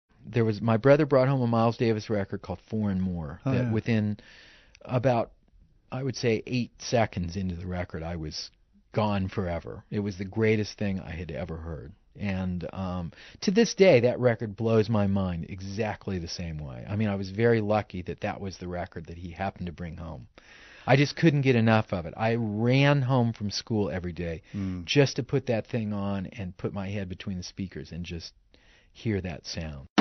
Pat Metheny Interview Clip